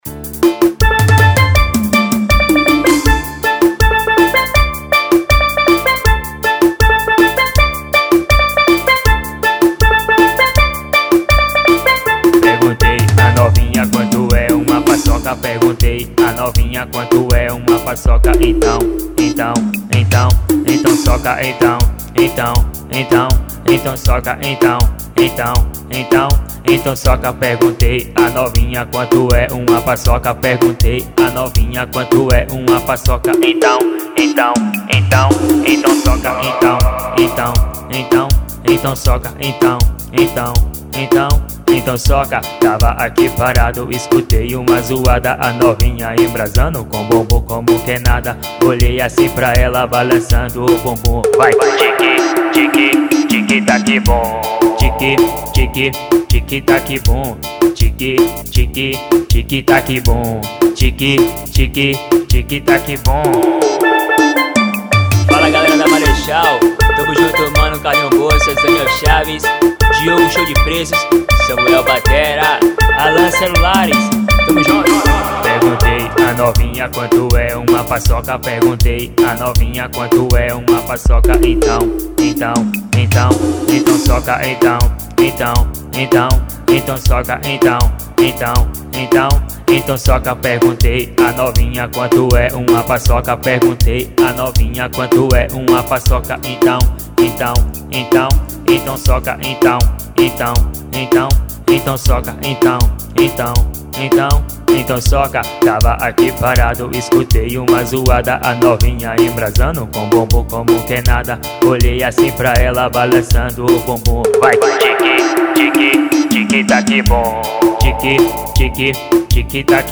EstiloArrochadeira